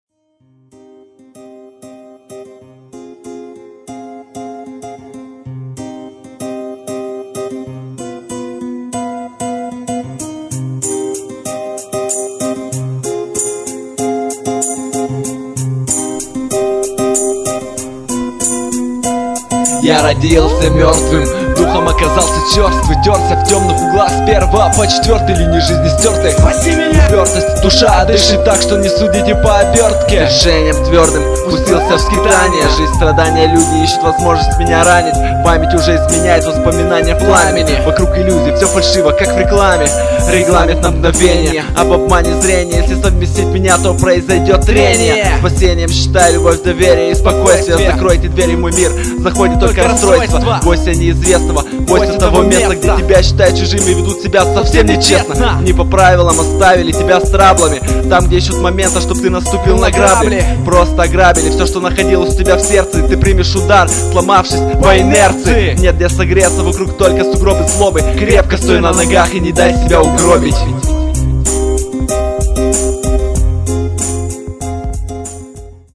2005 Рэп Комментарии